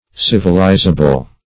Civilizable \Civ"i*li`za*ble\